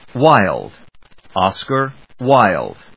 音節Wilde 発音記号・読み方/wάɪld/発音を聞く